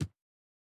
Land Step Stone A.wav